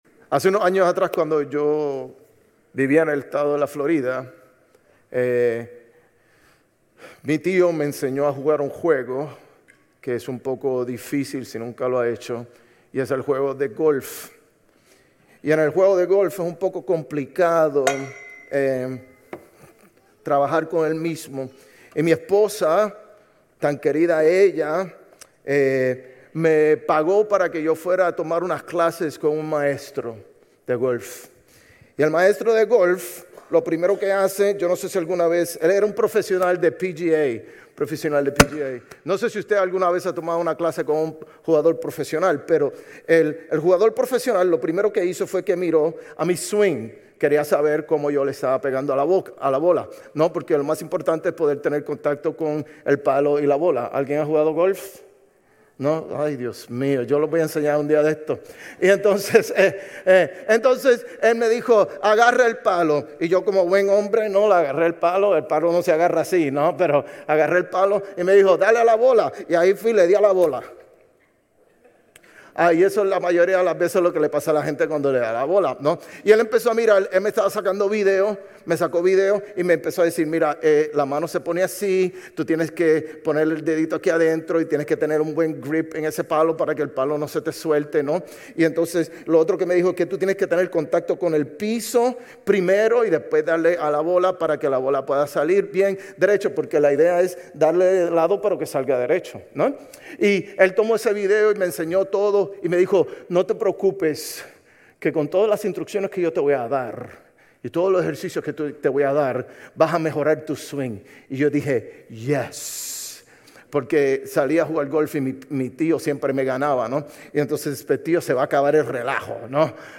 Sermones Grace Español 10_26 Grace Espanol Campus Oct 27 2025 | 00:36:42 1x 00:00 / 00:36:42 Subscribe Share RSS Feed Share Link Embed